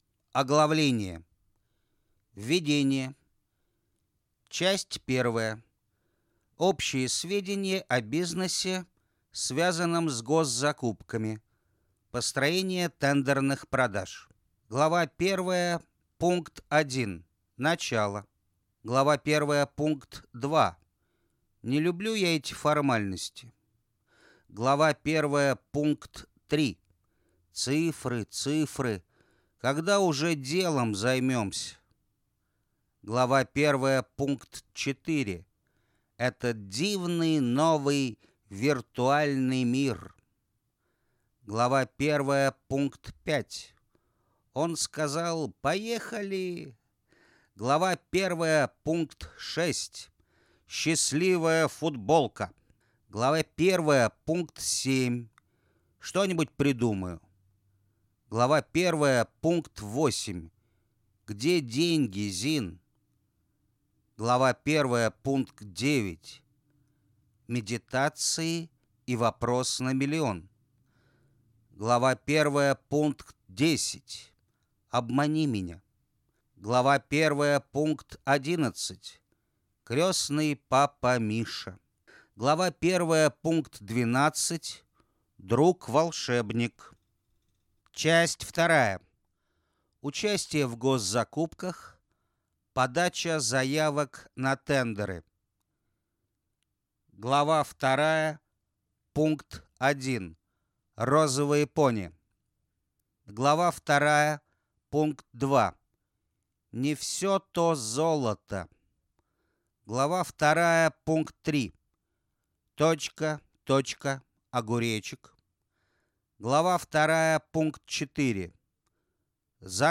Аудиокнига Госзакупки и тендеры для малого бизнеса. Как участвовать, чтобы побеждать | Библиотека аудиокниг